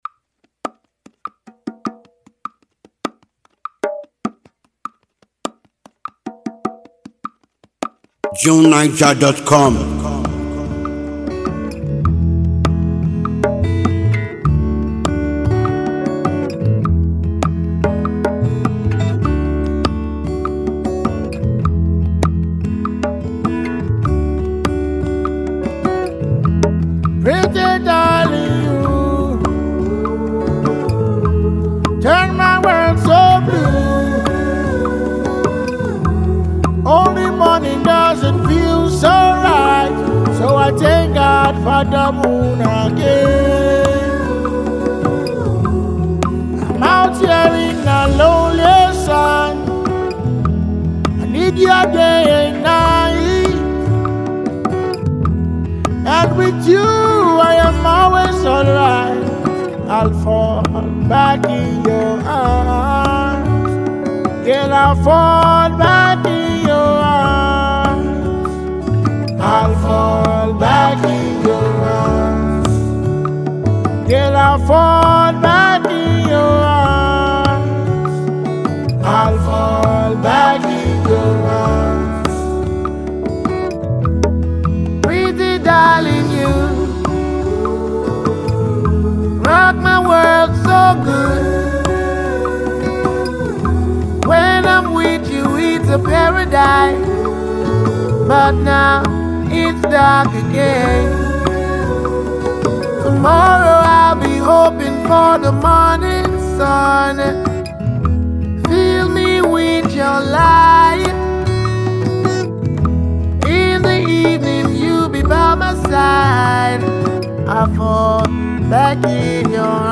afrobeat